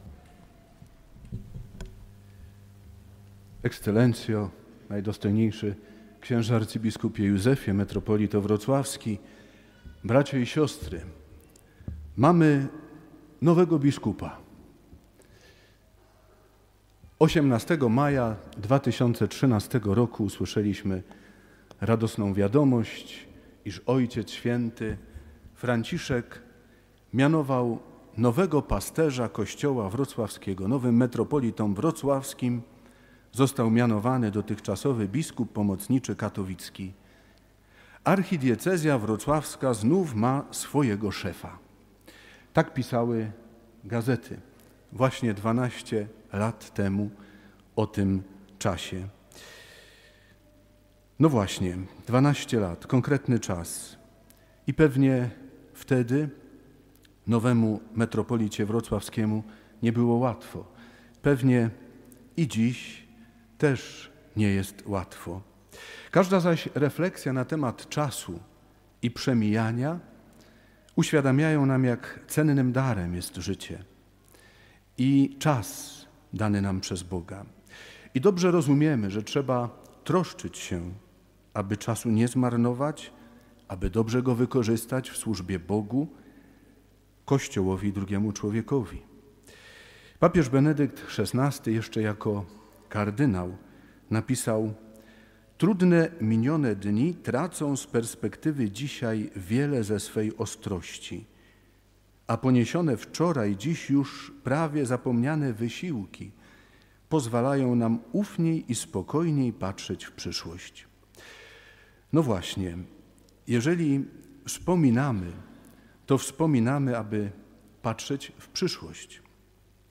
homilia_rocznica-ingresu.mp3